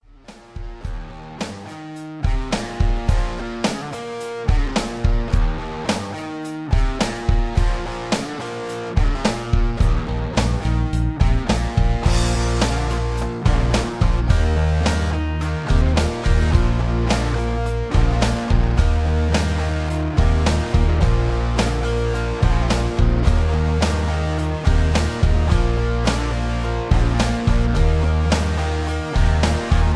karaoke collection , backing tracks